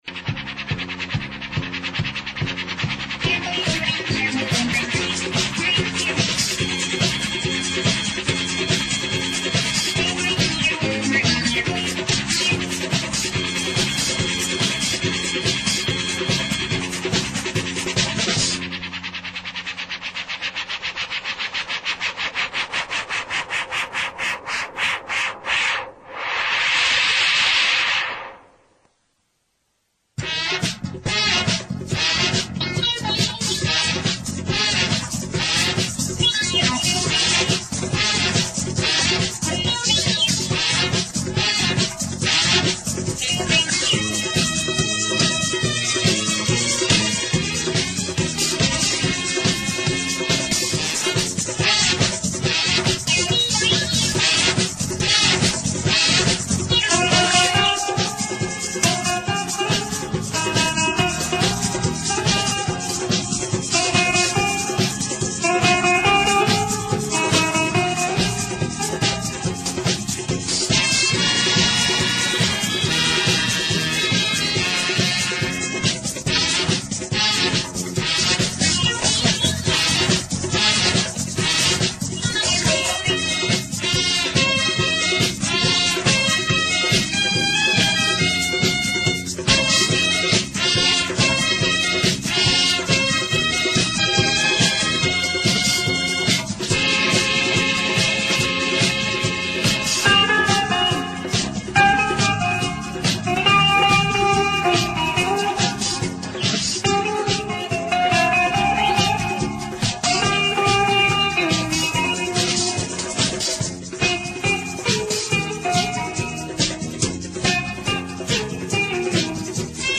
Home > Mp3 Audio Songs > Bollywood MP3 Songs 1980